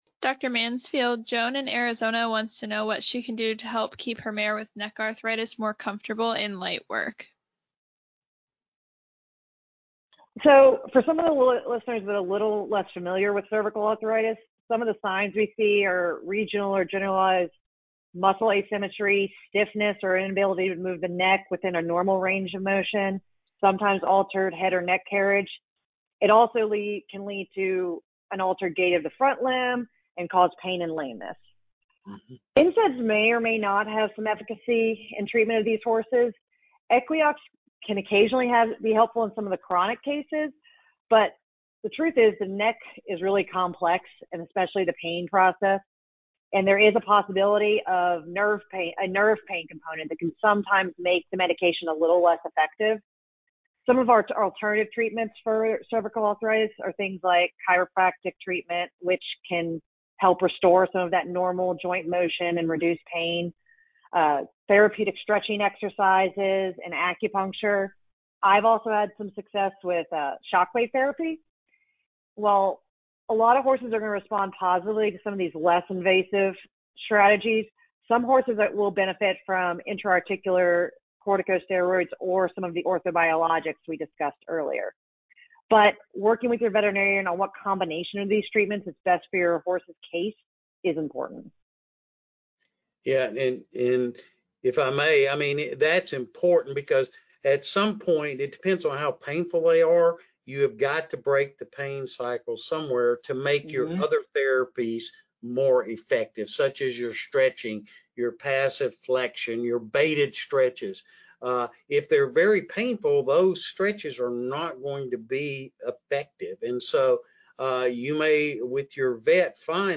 This podcast is an excerpt of our Ask TheHorse Live Q&A, "Equine Joint Care Therapies."